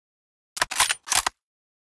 gamedata / sounds / weapons / l96a1 / bolt.ogg.bak
bolt.ogg.bak